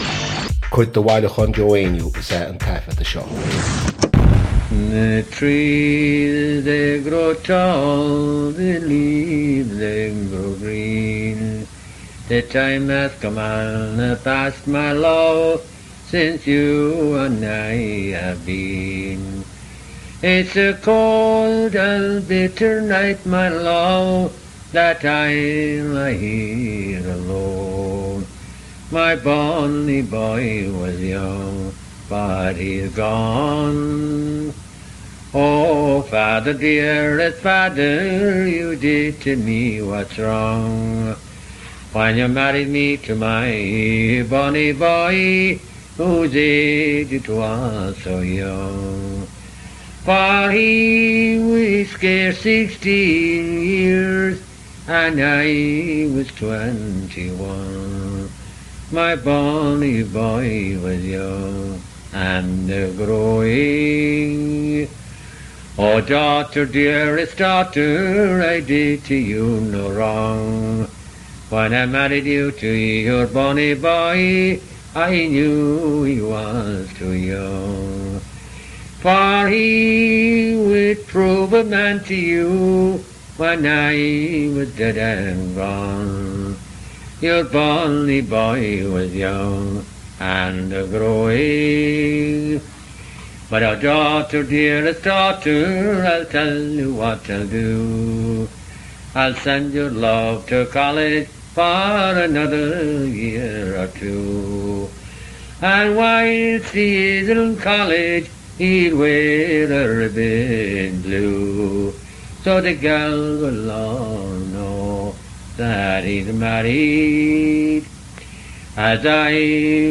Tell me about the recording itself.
• Suíomh an taifeadta (Recording Location): University of Washington, United States of America.